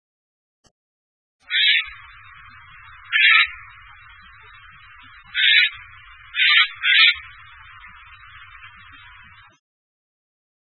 〔カケス〕ジェーイッ（ジャージャー）／山地の林に棲息，普通・留鳥，33p，雌雄同
kakesu.mp3